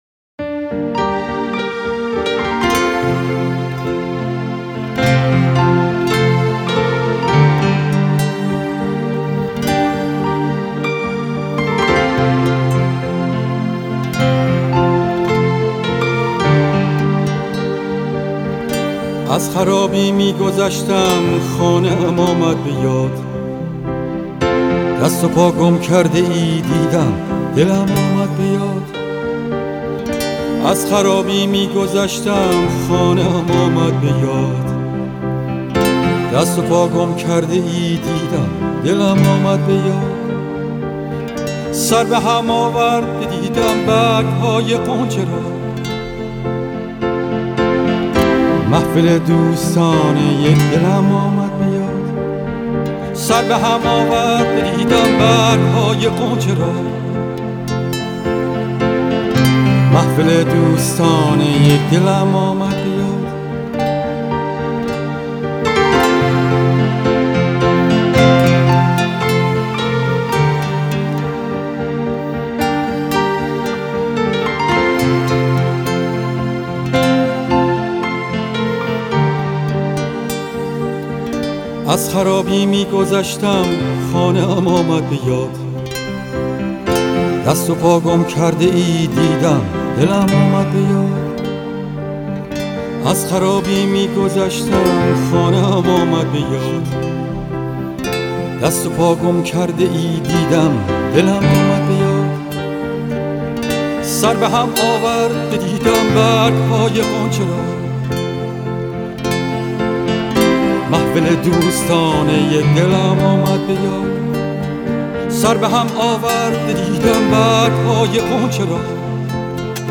نوستالژیک و پر احساس
موسیقی اصیل